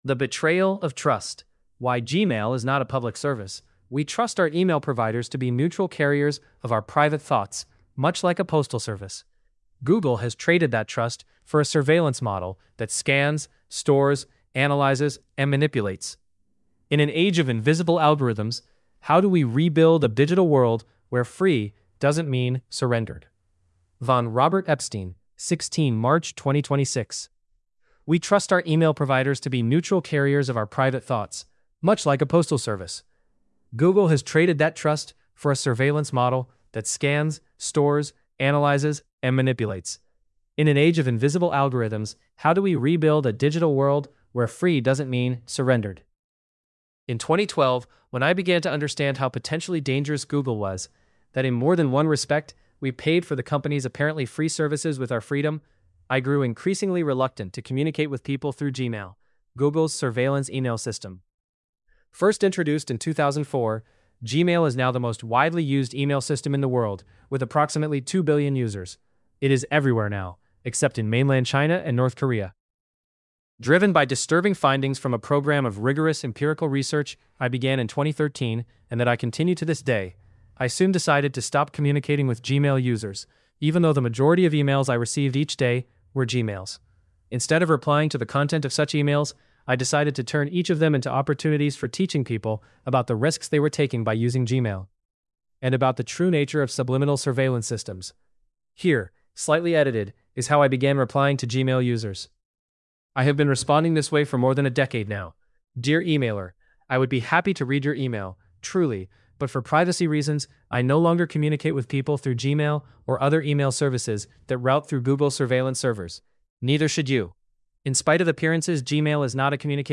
epstein_english_male.mp3